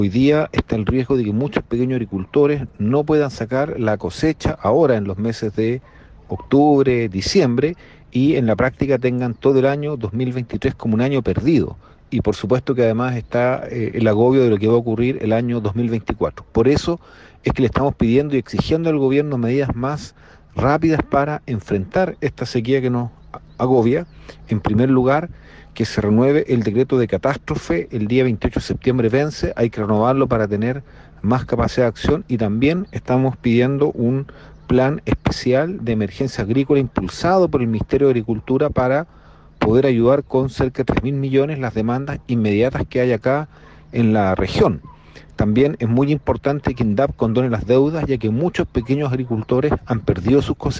Ante esta situación de emergencia, diferentes autoridades llegaron hasta la localidad de El Palqui, con el fin de dialogar con los afectados y buscar soluciones concretas.
El senador Núñez recalcó que